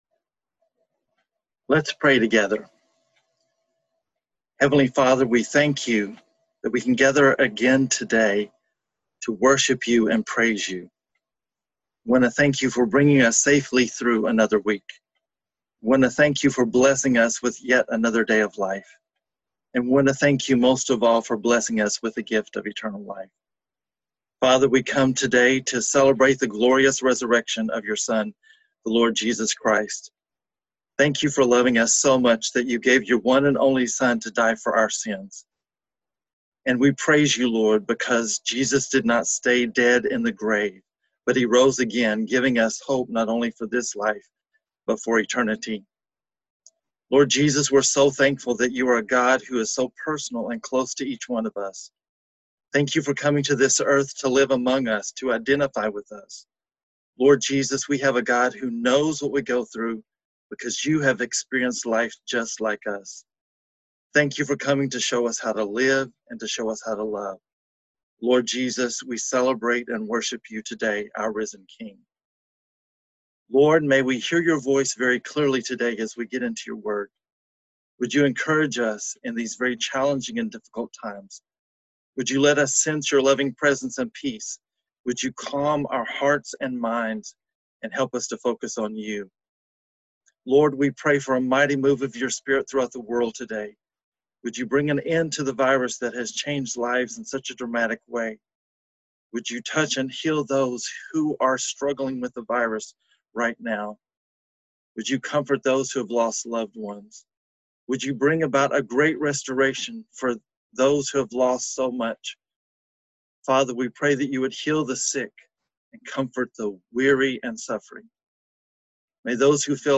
Easter-Message_Audio.mp3